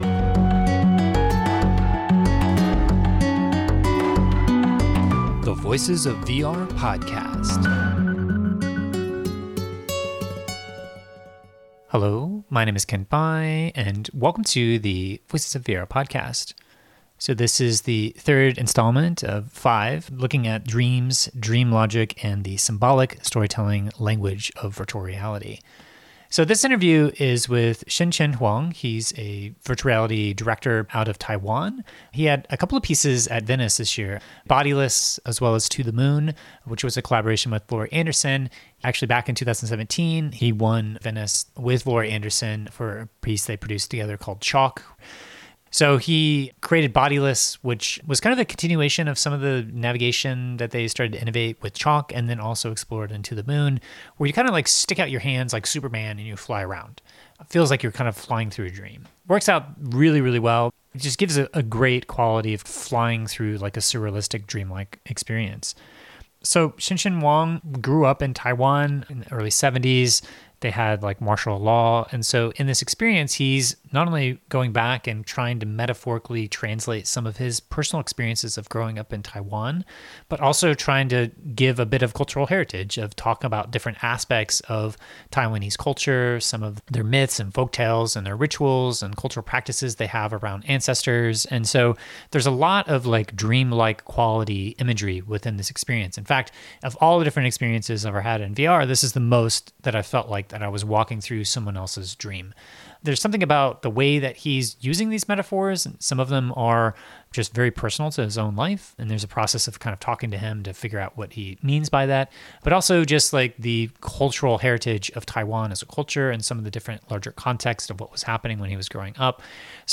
at the Venice International Film Festival where we talked about his journey into VR